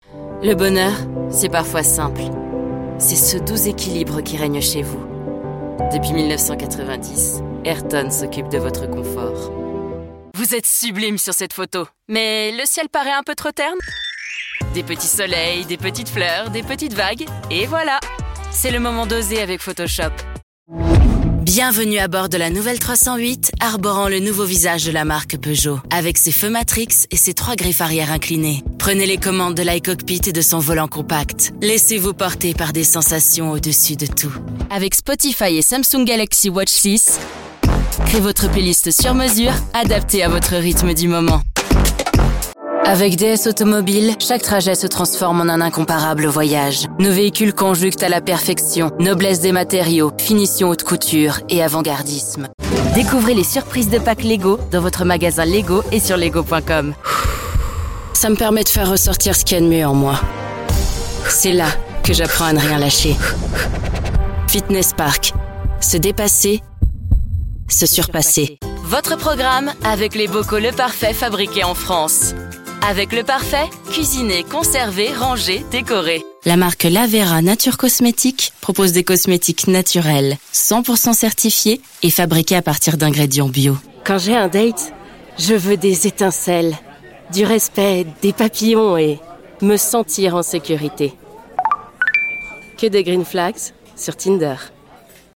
Konversation
Energiegeladen
Warm